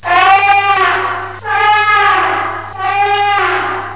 ELEFANT.mp3